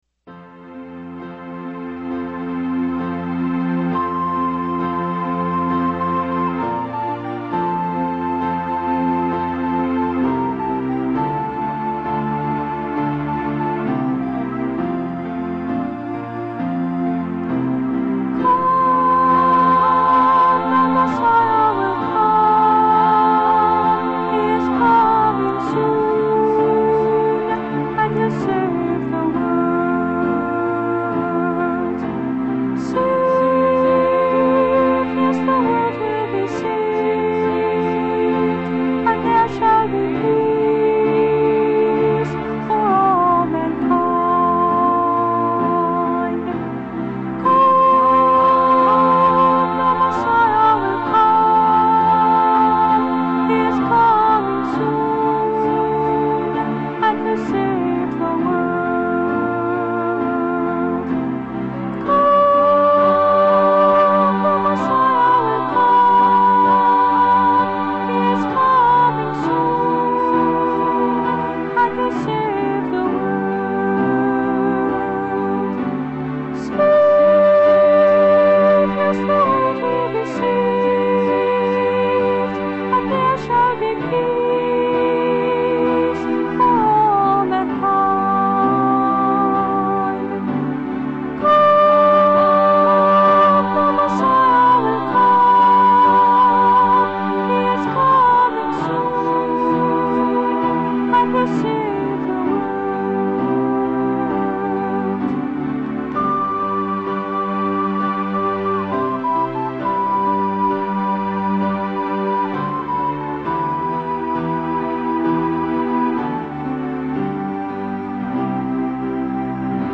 The beauty of this song is in the music, 4-part vocal harmonies, and the feeling of hope and anticipation it invokes.
Instrument: “Strings” and “Piano” on unknown model digital piano, Yamaha soprano recorder